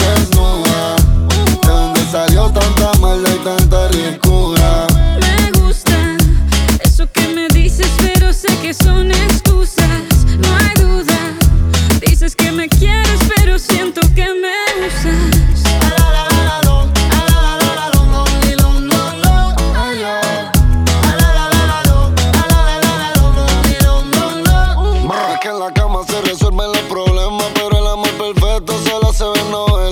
Genre: Pop Latino